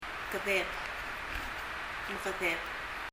« long 長い big, large 大きい » short 短い kedeb [kɛðɛb] 英） short 日） 短い 複数形： mekedeb [məkɛðɛb] 単数形・複数形、合わせて発音してもらいました Leave a Reply 返信をキャンセルする。